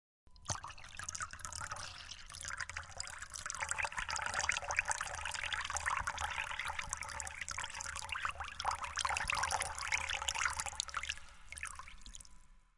自然, 水, 白水, 瀑布 " 15 Y08 M04 Multnomah Falls Upper Falls River Close Up Trickle T24
描述：平静的水特写镜头记录通过小卵石和岩石。 背景中有激流的氛围。
标签： 涓涓细流 平静
声道立体声